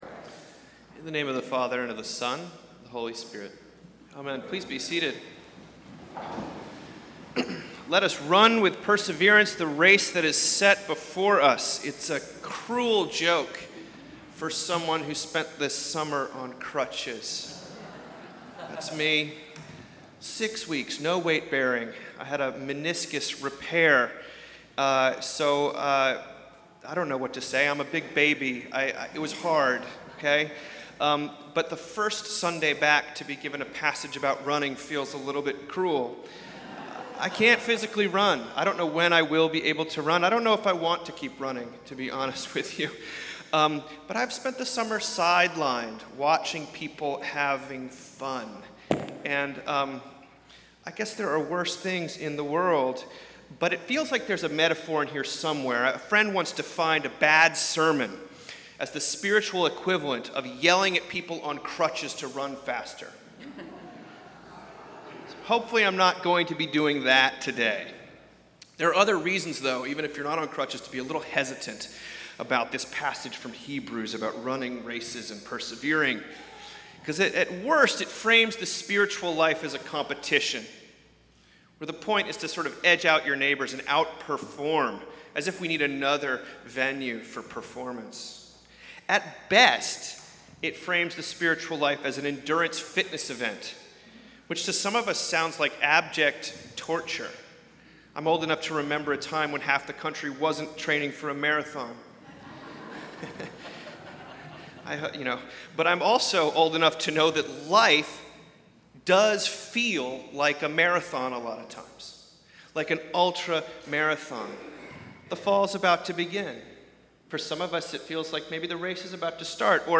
Venue: Christ Church Charlottesville Scripture: Hebrews 12:1-2